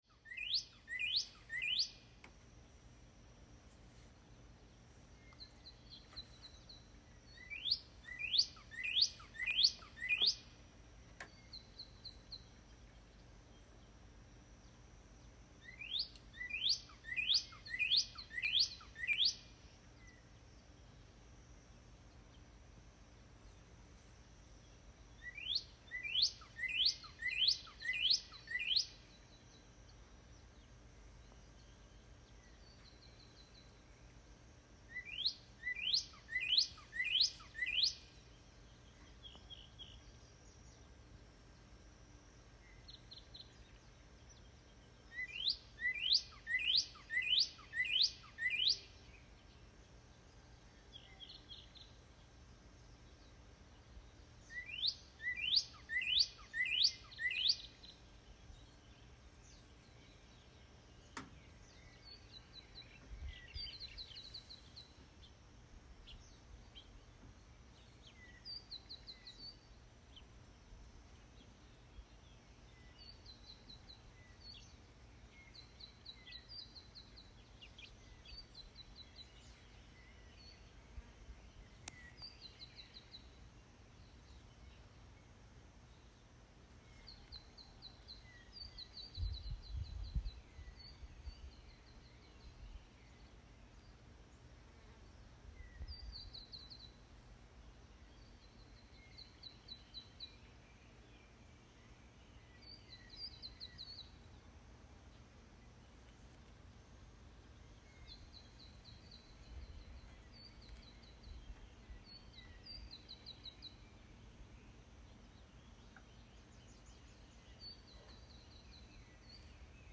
Cardenal Rojo (Cardinalis cardinalis)